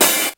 • High Quality Natural Rap Open Hat Sound D Key 04.wav
Royality free open hat tuned to the D note. Loudest frequency: 6690Hz
high-quality-natural-rap-open-hat-sound-d-key-04-ShT.wav